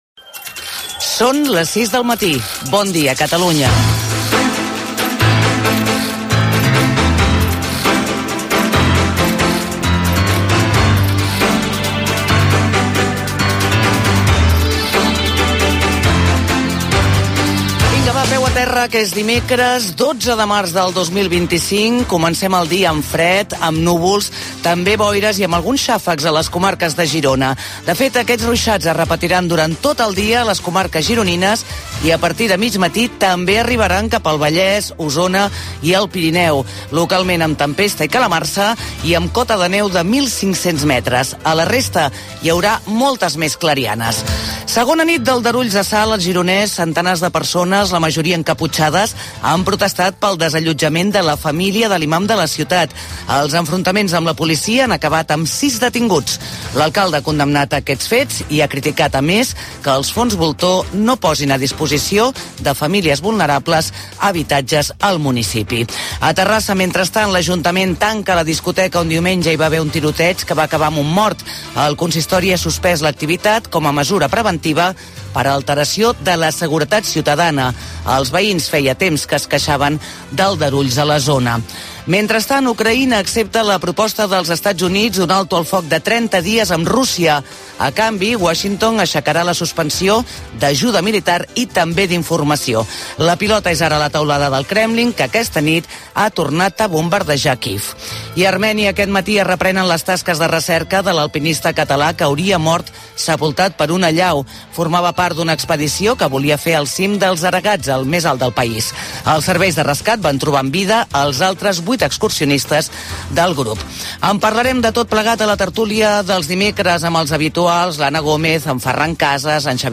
El món a RAC 1 Gènere radiofònic Info-entreteniment